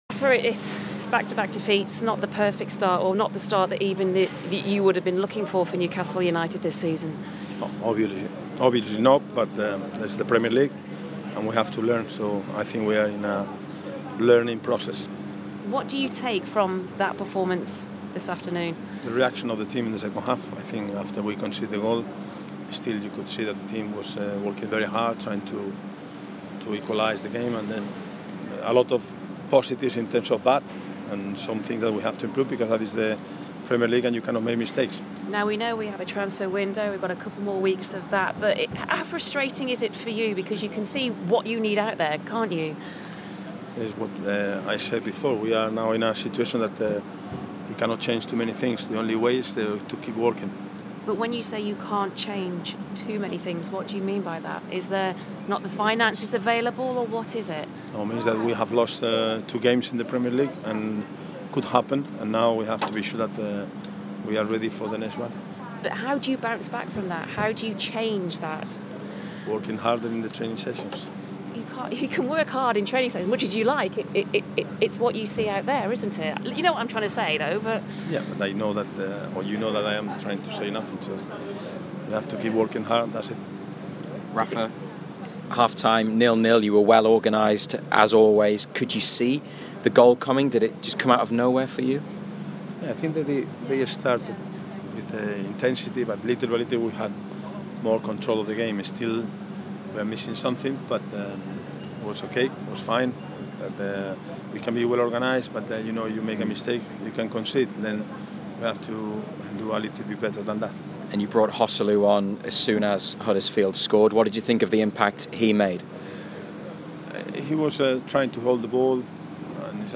Rafa Benítez spoke to the BBC after the Magpies slipped to a narrow defeat at Huddersfield.